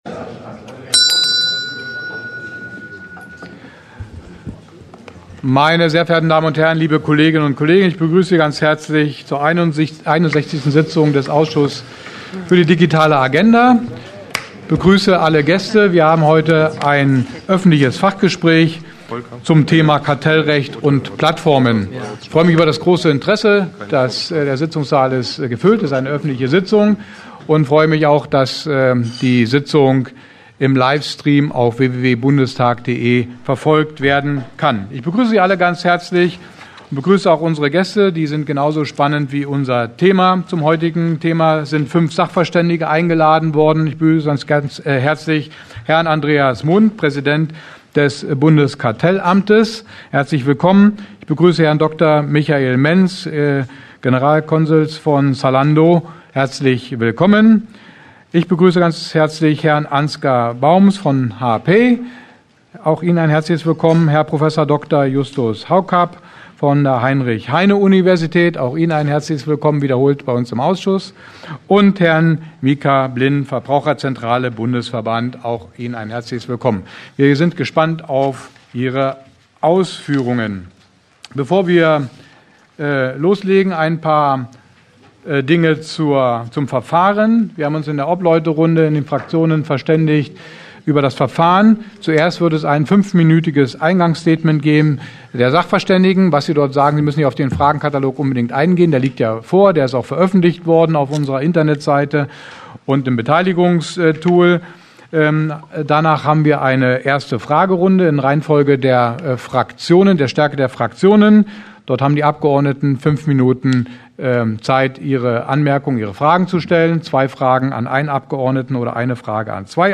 Öffentliche Sitzung des Ausschusses Digitale Agenda